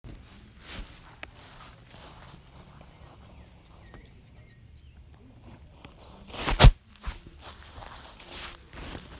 Parear un balón CARTAGO